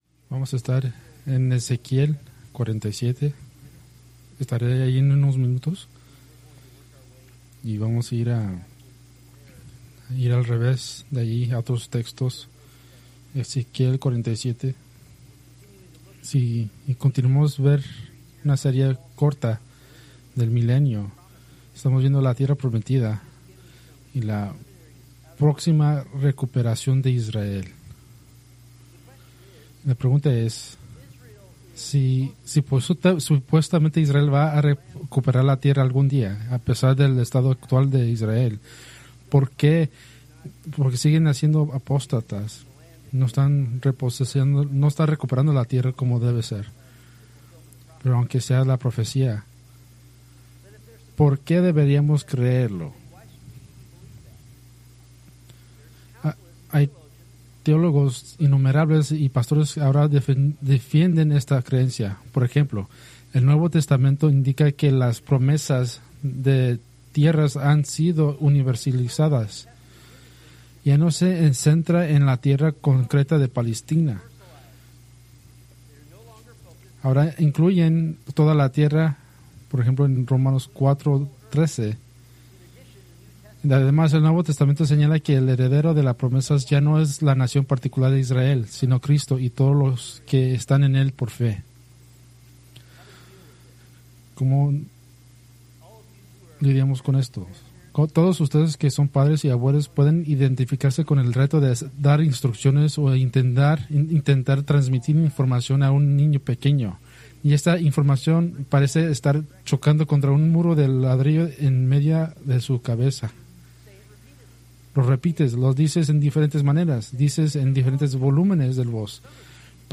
Preached February 22, 2026 from Escrituras seleccionadas